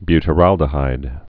(bytə-răldə-hīd)